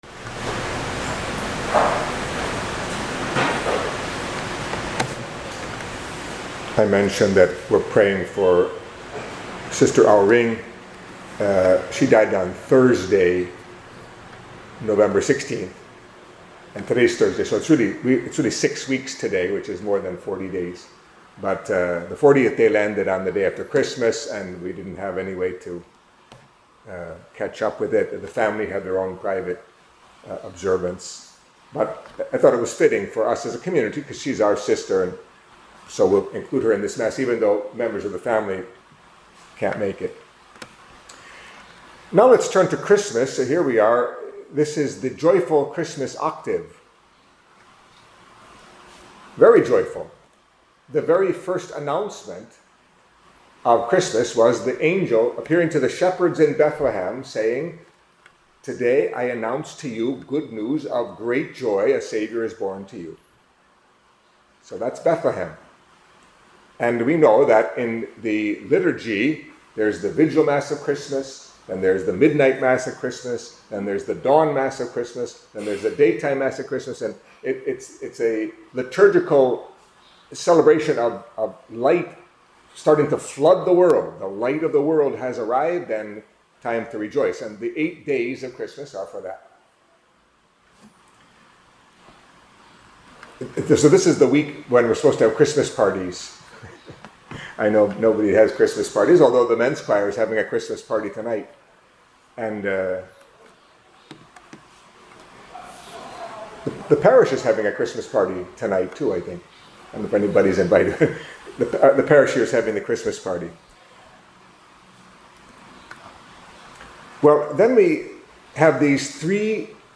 Catholic Mass homily for the Feast of the Holy Innocents